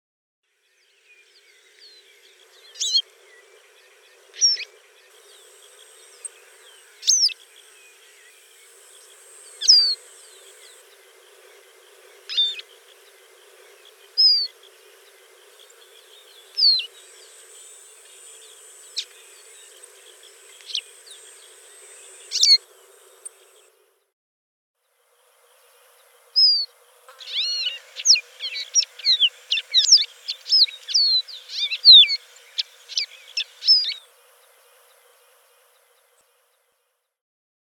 Distinguishing females of capuchino seedeaters: calls repertoires provide evidence for species-level diagnosis PDF Supplemental material